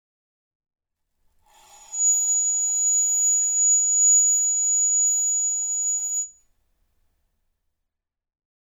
MONO
Science Museum, London
“vps” refers to vibrations per second
Tuning Forks